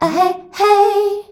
AHEHEY  E.wav